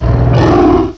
sovereignx/sound/direct_sound_samples/cries/landorus_therian.aif at afc7795afc65e08fff59e2d8b171459c555aa810